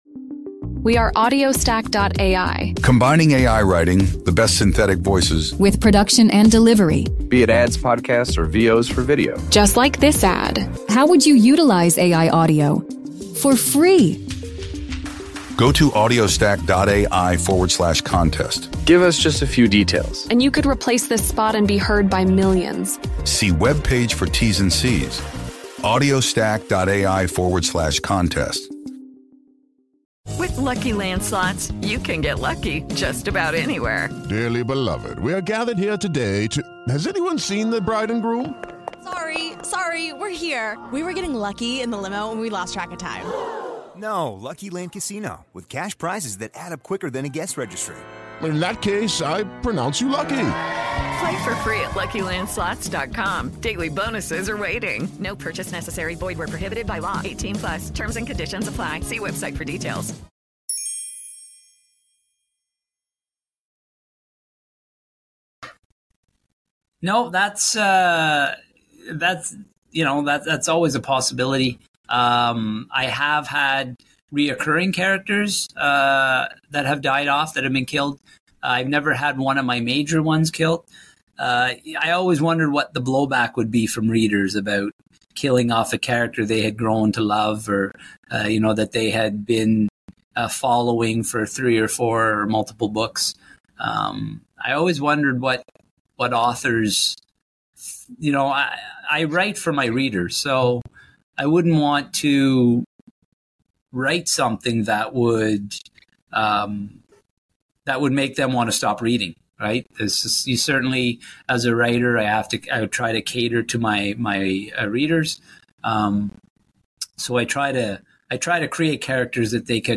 Get ready for a suspense-filled journey as we unveil the secrets behind the gripping thriller novel, 'The Cradle Will Fall.' In this exclusive interview, we dive deep into the heart-pounding twists, unforgettable characters, and intense moments that make this suspense thriller a must-read.